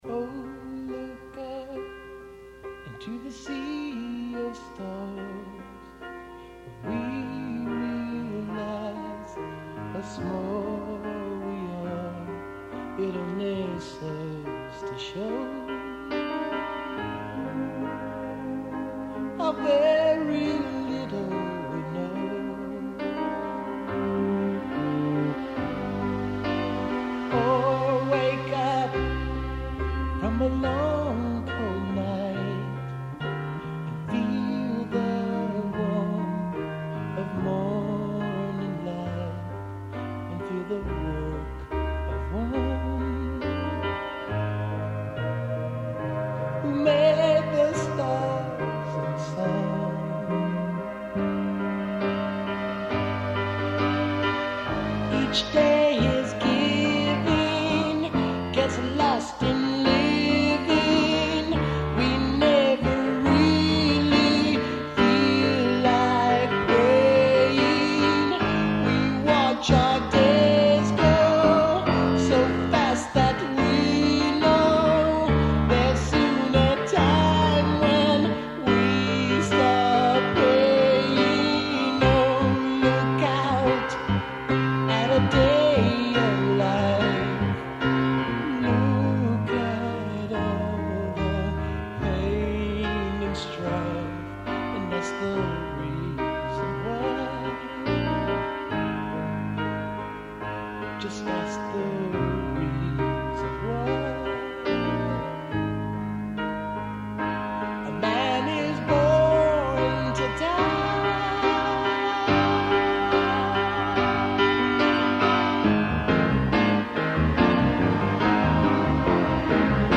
Vocal, Piano, Mellotron, Moog
Bass